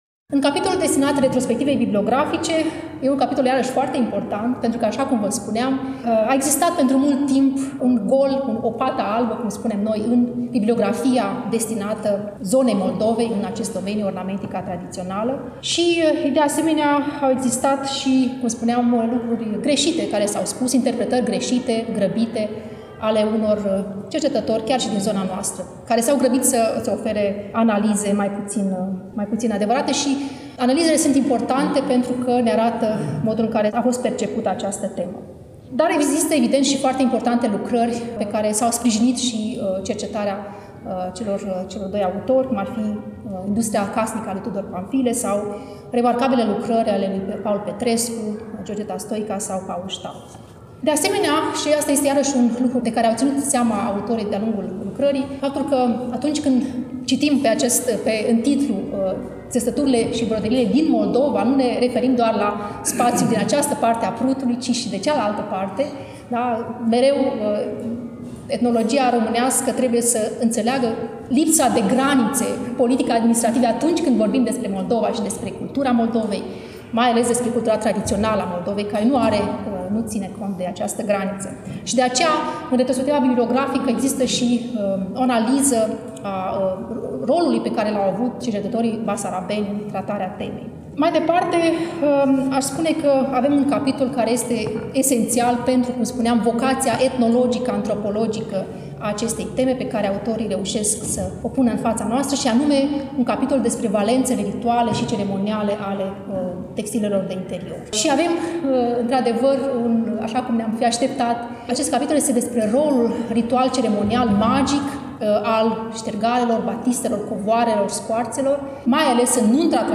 Cartea a fost lansată, la Iași, nu demult, în sala „Petru Caraman” din incinta Muzeului Etnografic al Moldovei, Palatul Culturii.